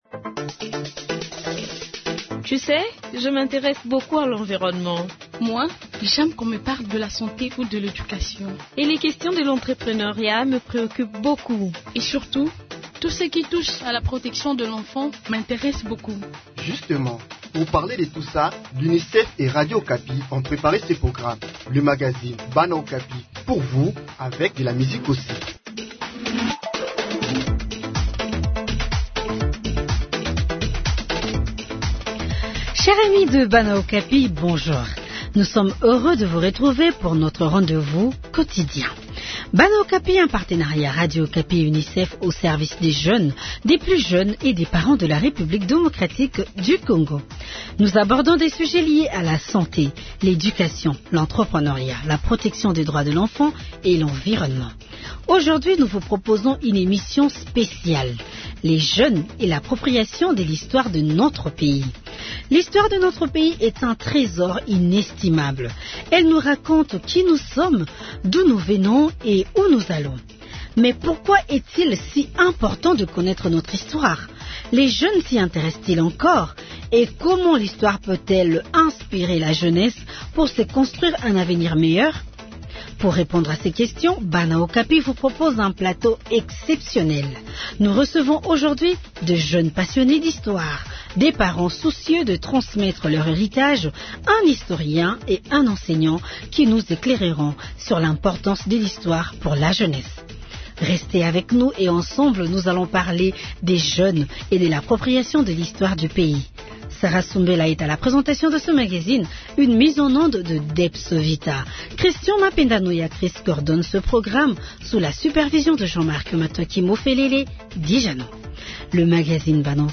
Nous recevons aujourd'hui des jeunes passionnés d'histoire, des parents soucieux de transmettre leur héritage, un historien et un enseignant qui nous éclaireront sur l'importance de l'histoire pour la jeunesse.